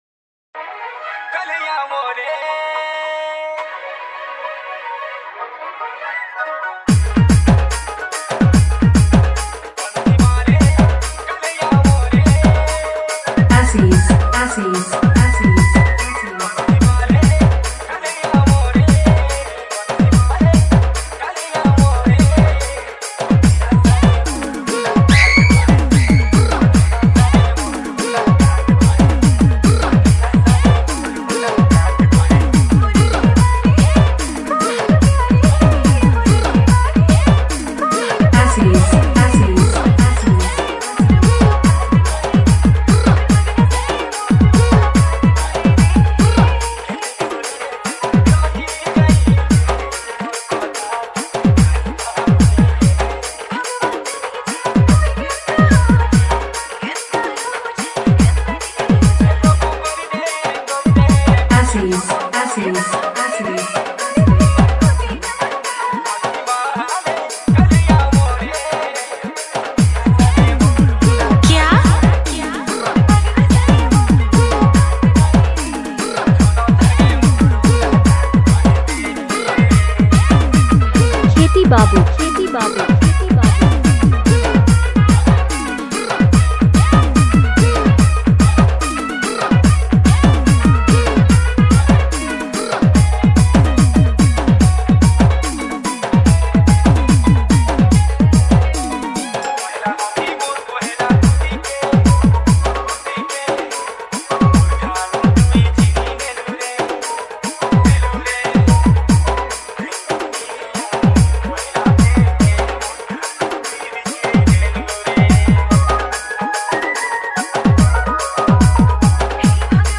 SAMBALPURI BHAJAN DJ REMIX